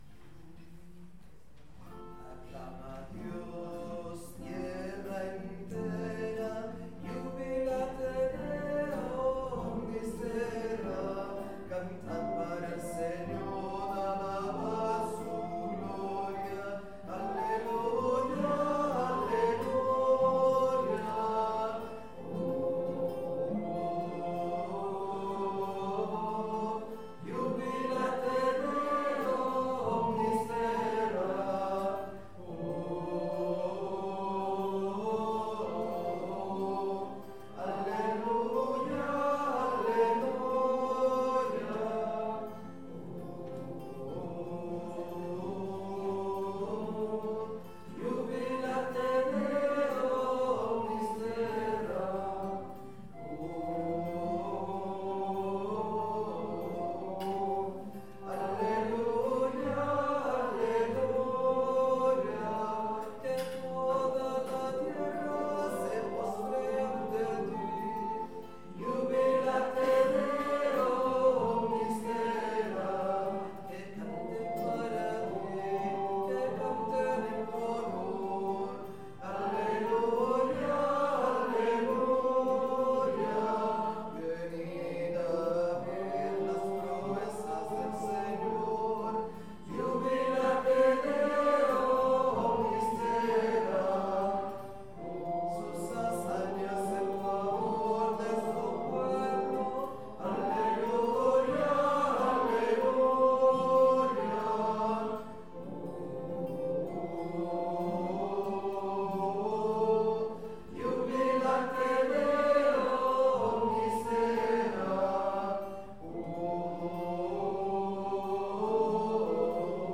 Pregària de Taizé a Mataró... des de febrer de 2001
Parròquia Maria Auxiliadora - Diumenge 26 de març de 2023
Vàrem cantar...